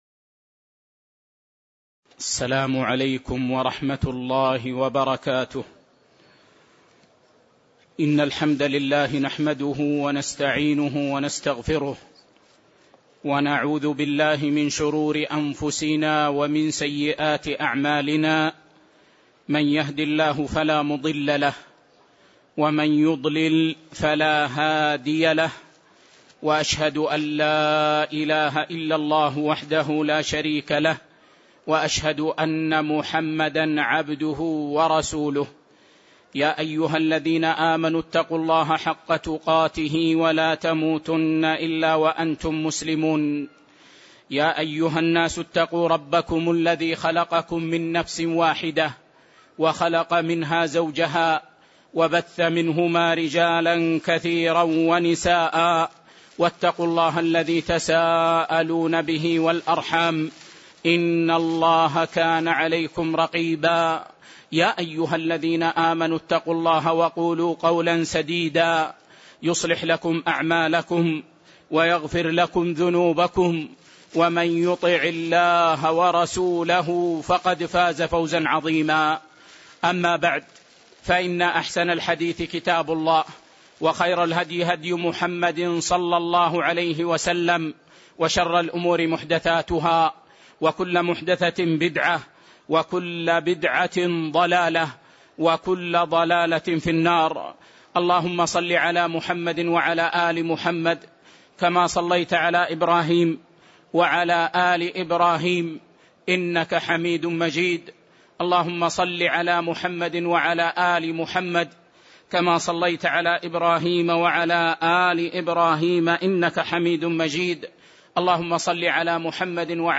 تاريخ النشر ٢٥ صفر ١٤٣٨ هـ المكان: المسجد النبوي الشيخ